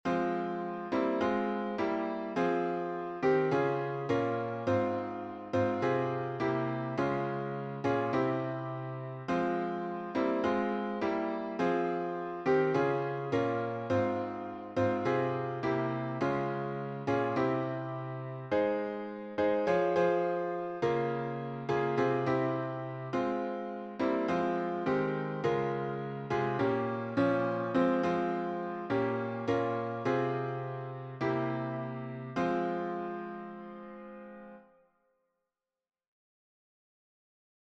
Transylvanian hymn tune